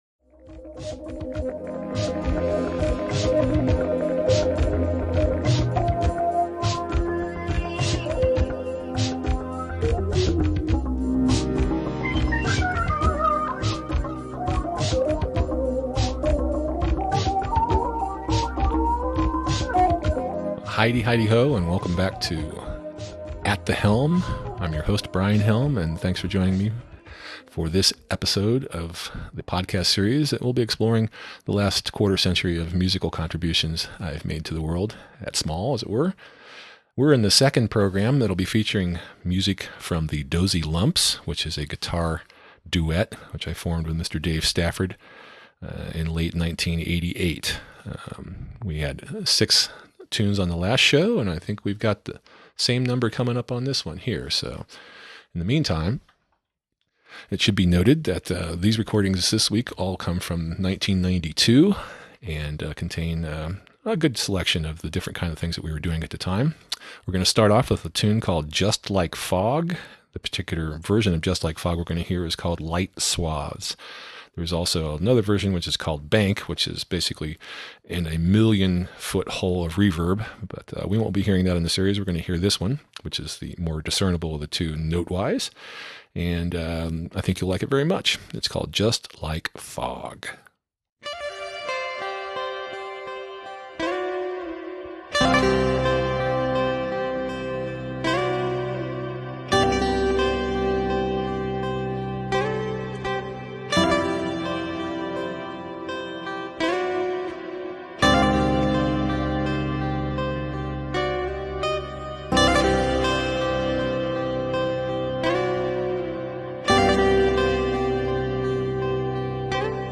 the guitar duet
playing live in the studio circa 1992